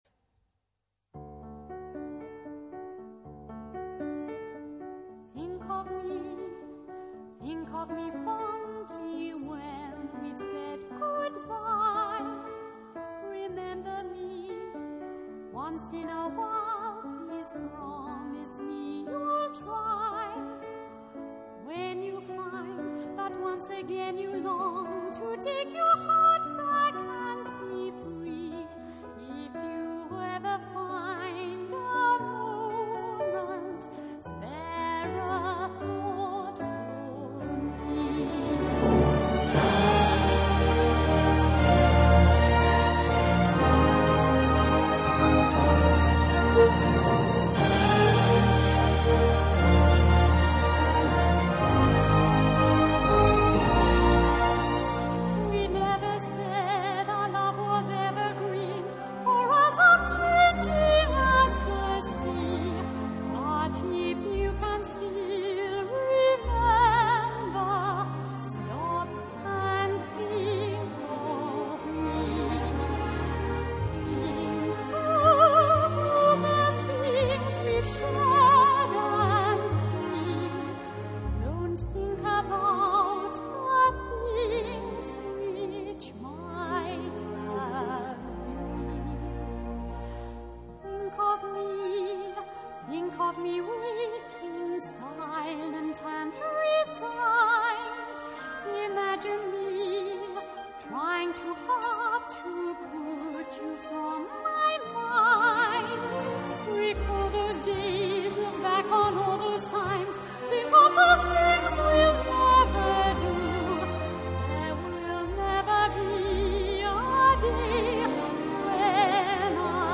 精選輯中就只有 Christine 唱的部份，但是已經足夠了！